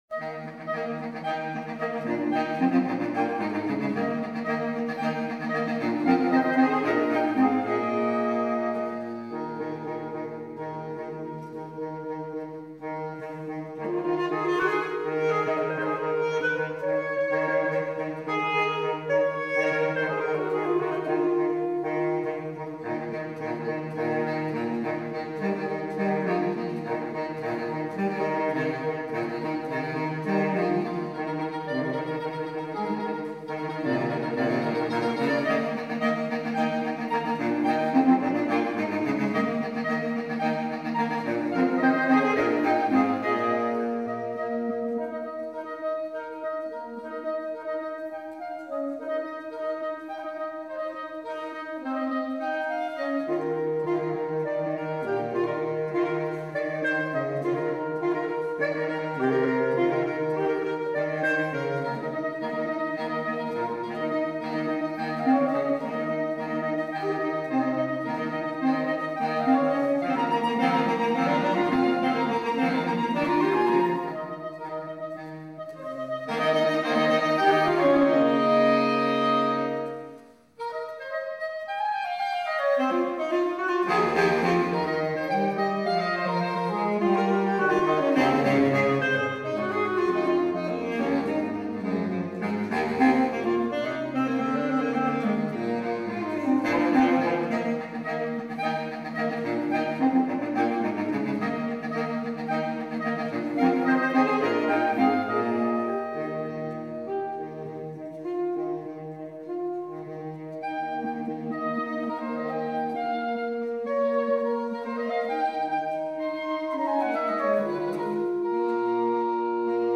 for saxophone quartet duration 16’ View Score Movement III: "Pappy Shaw" Each movement in Choreography is derived from a specific American dance style, adapted for saxophone quartet. The first movement is inspired by early hip-hop, the second by the Charleston, the third movement is a hoe-down, and the final movement is based on swing dancing.
for saxophone quartet duration 16’